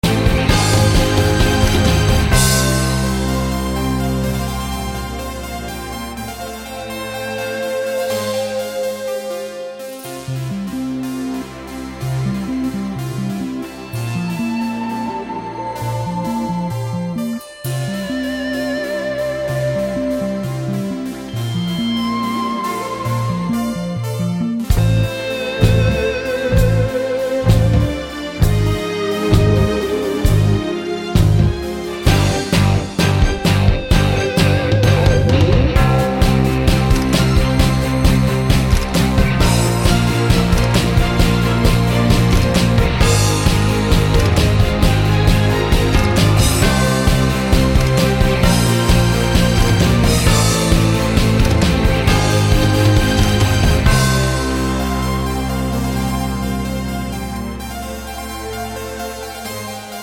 no BV Pop (1970s) 3:48 Buy £1.50